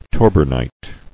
[tor·bern·ite]